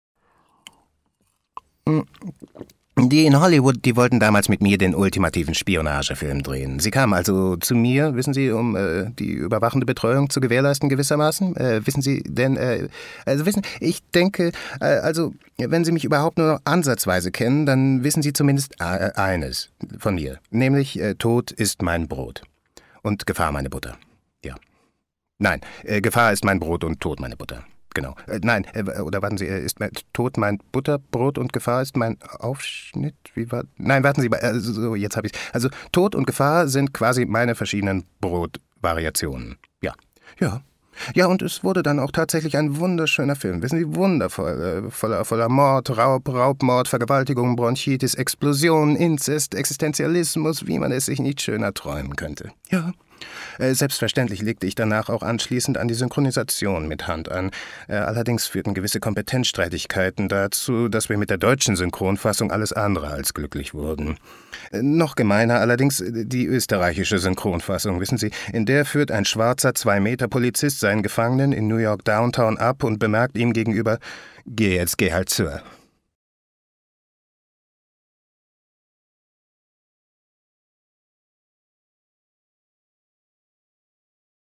Erzählung: Woody Allen – What’s up, Tiger Lily?